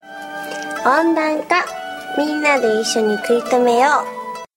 Di sini kita bisa mendengar senryu-senryu yang dibacakan.